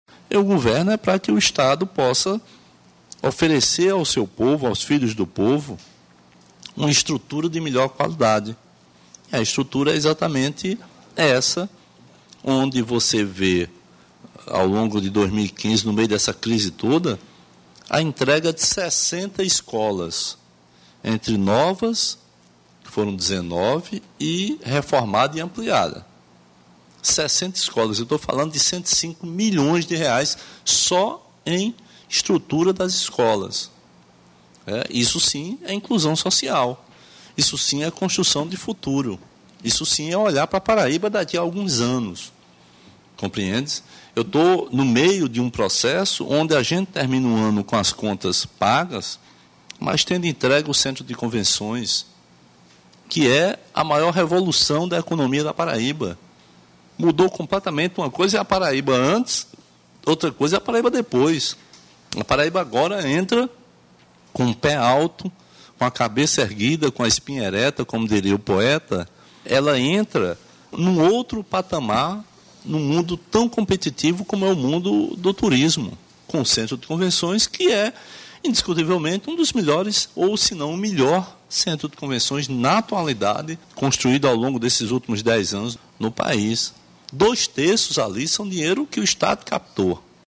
O governador Ricardo Coutinho participou nesta segunda-feira, 28, da última edição do programa semanal de rádio ‘Fala, Governador’ prestando contas e falando sobre algumas obras e ações do Governo do Estado durante o ano de 2015.